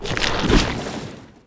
死亡倒地zth070523.wav
通用动作/01人物/02普通动作类/死亡倒地zth070523.wav
• 声道 立體聲 (2ch)